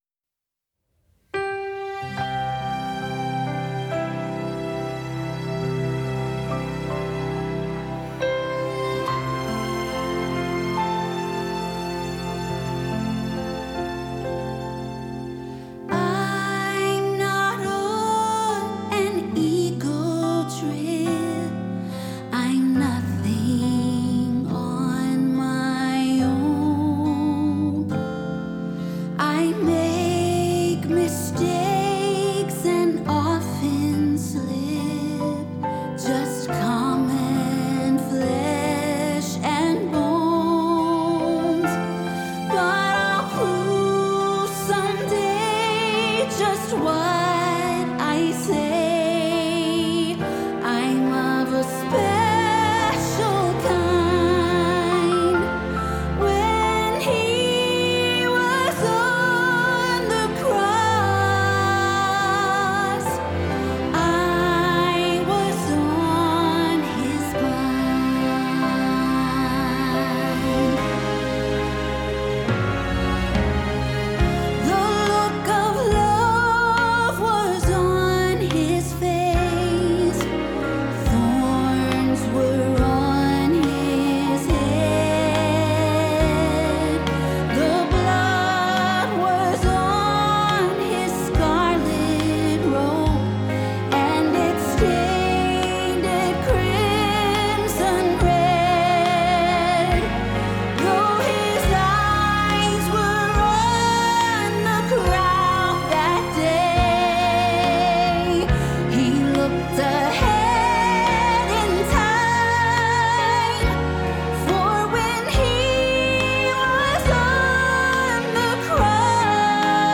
Tenor playlist